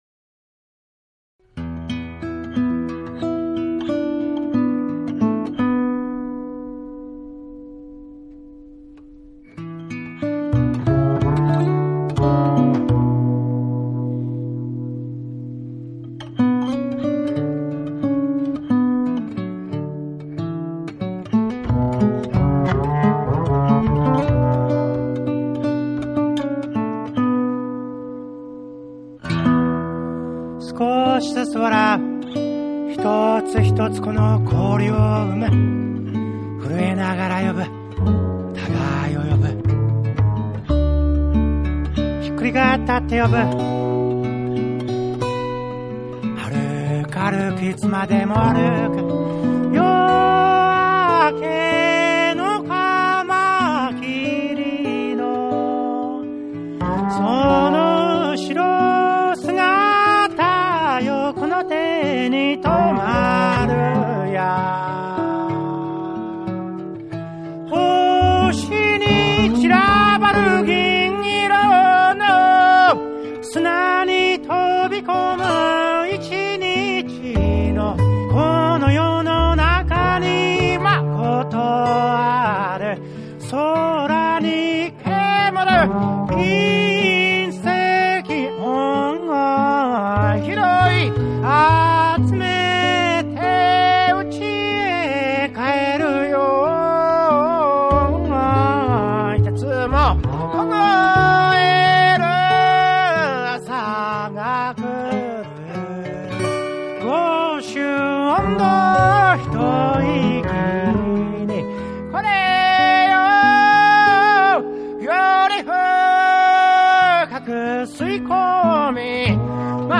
シンプルながらも繊細で力強い音楽が詰まった唯一無二の一枚が誕生した。
JAPANESE / NEW WAVE & ROCK / NEW RELEASE(新譜)